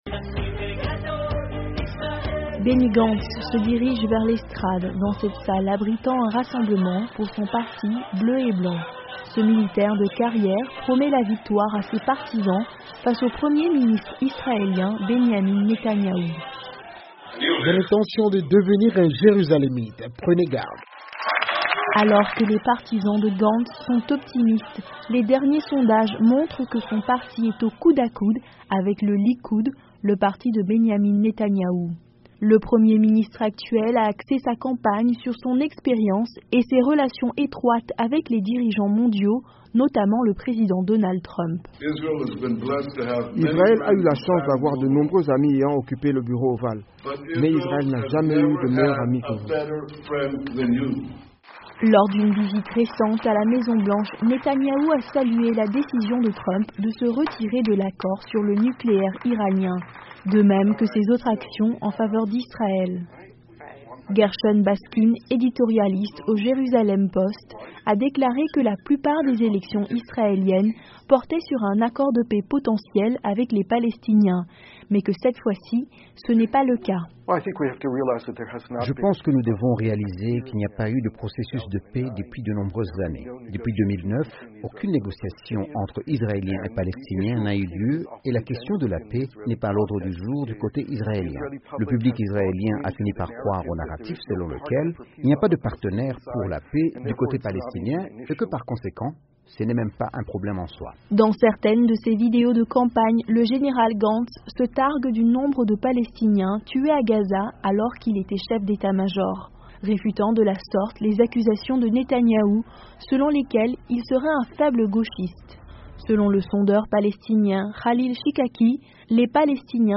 Cette élection est considérée comme un référendum sur les 12 années de Netanyahu à la tête du pays. Un reportage